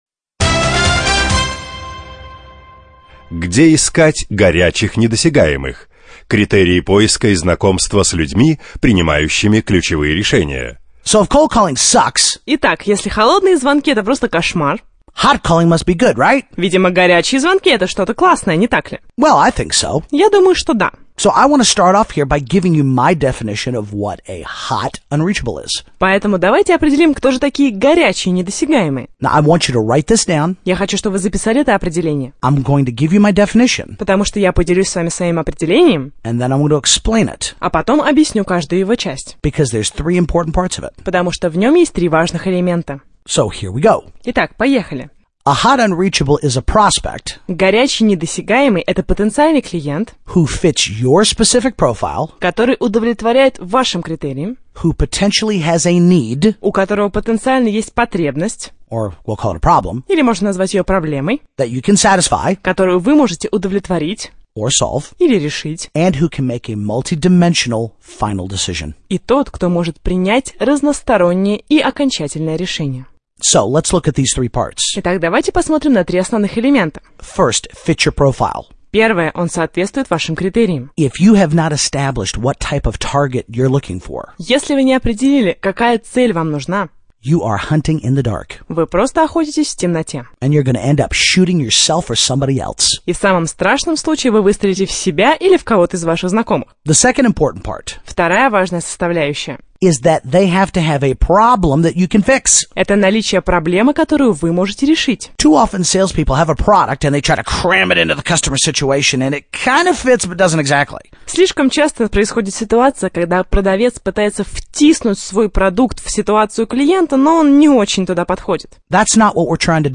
Джон Вон Эйкен Аудиокнига : Недосягаемые Загрузил на сайт